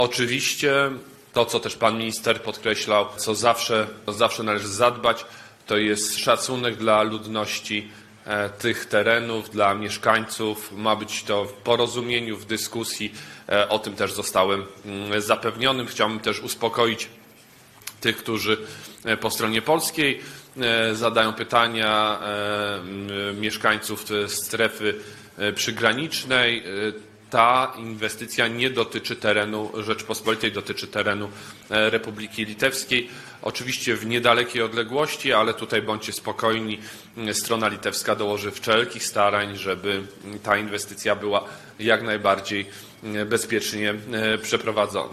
– Chcę uspokoić (…) mieszkańców strefy przygranicznej. (…) Bądźcie spokojni – mówił szef MON-u podczas konferencji z udziałem Robertasa Kaunasa, ministra obrony Litwy.